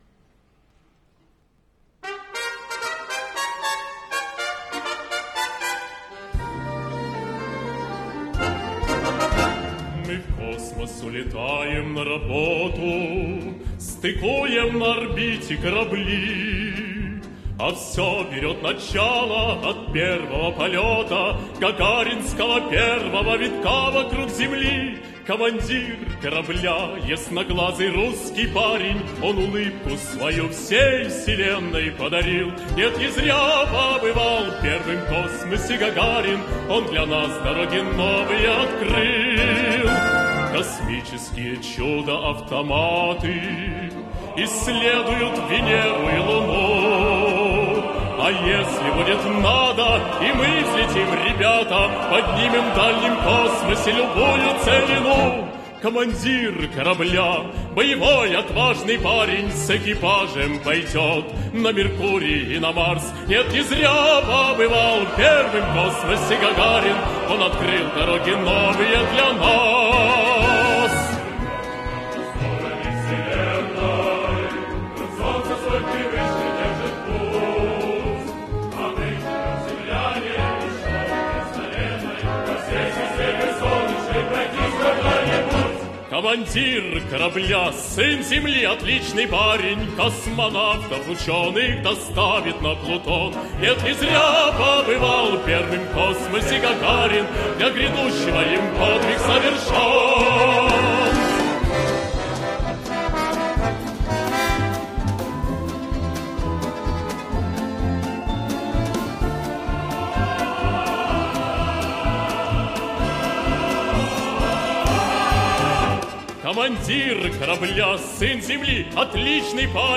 Марши
С концерта в КЗЧ 22 апреля 2024 года